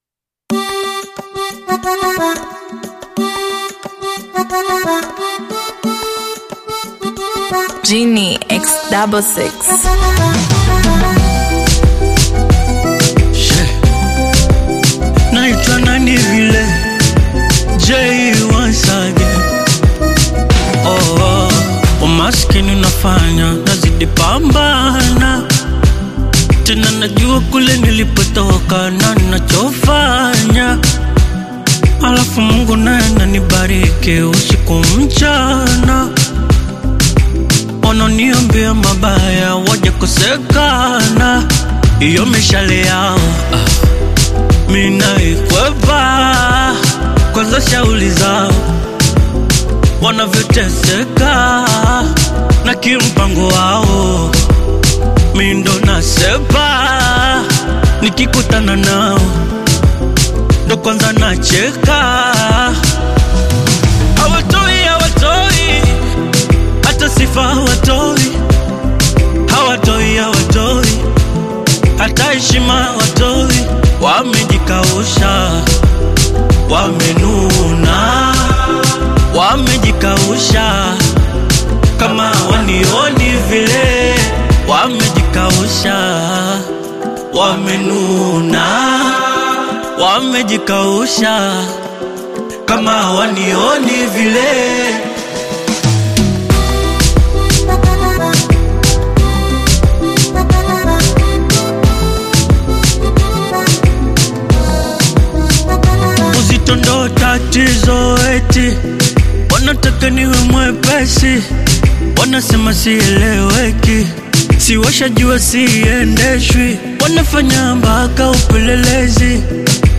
Tanzanian singer